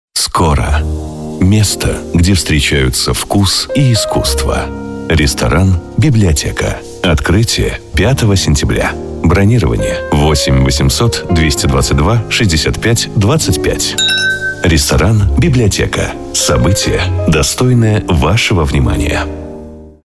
Солидный вариант для представительских аудиорешений. Идеальный голос для закадрового озвучивания.
Тракт: rode nt2000, карта RME babyface Pro, акустическая кабина.